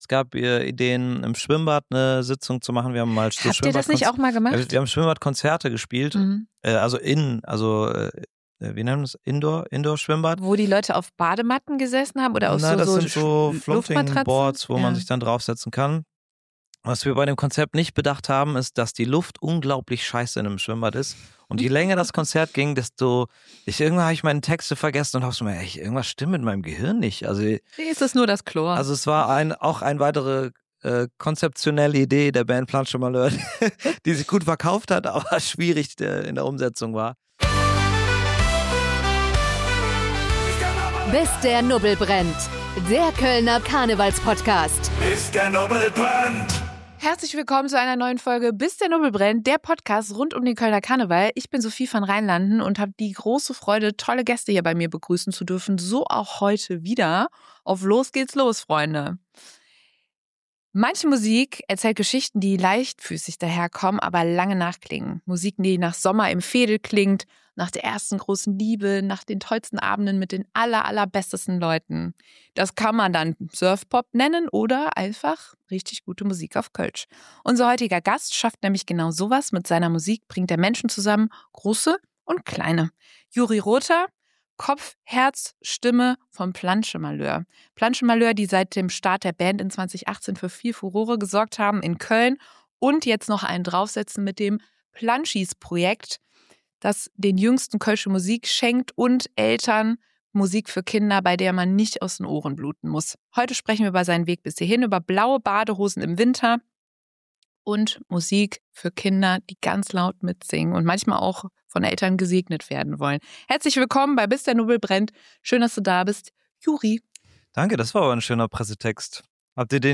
Podcast-News